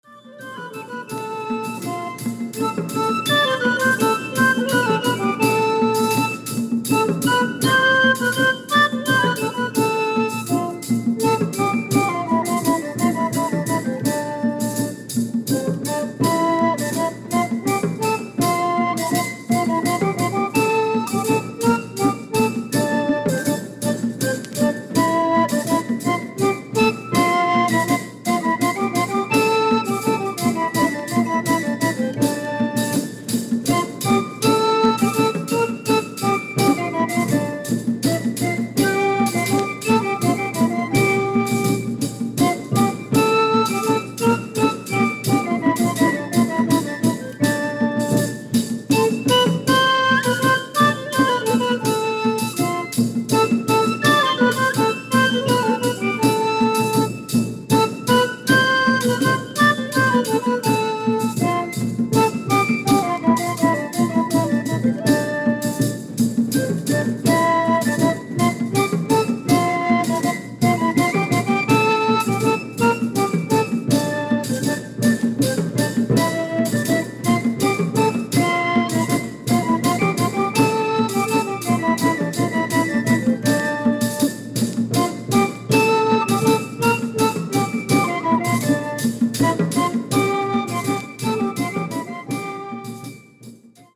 のんびり古楽に浸るのもステキです。